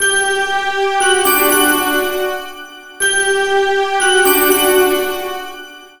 New Age